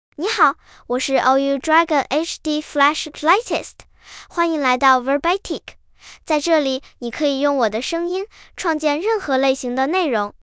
Xiaoyou Dragon HDFlash Latest — Female Chinese (Mandarin, Simplified) AI Voice | TTS, Voice Cloning & Video | Verbatik AI
Xiaoyou Dragon HDFlash Latest is a female AI voice for Chinese (Mandarin, Simplified).
Voice sample
Listen to Xiaoyou Dragon HDFlash Latest's female Chinese voice.
Xiaoyou Dragon HDFlash Latest delivers clear pronunciation with authentic Mandarin, Simplified Chinese intonation, making your content sound professionally produced.